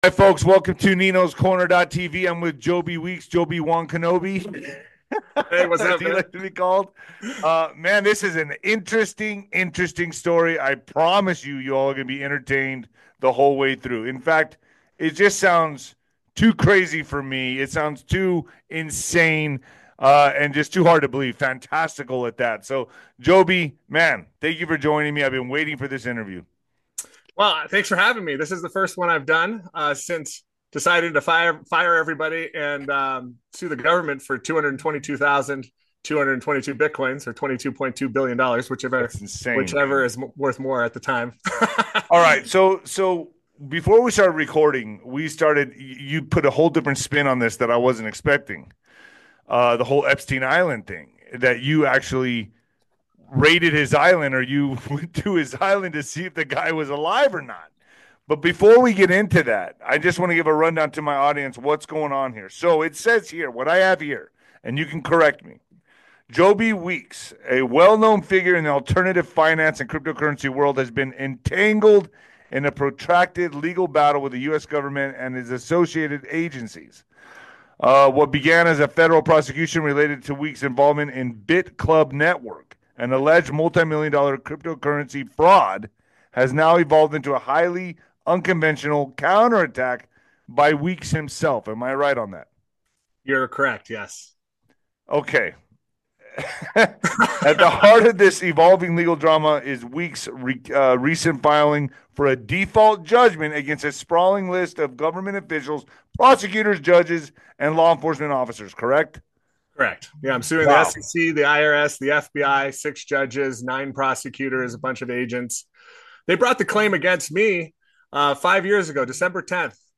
The interview also covers his background as an entrepreneur, his involvement in various businesses, and his political activism.